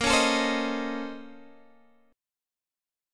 A#mM11 chord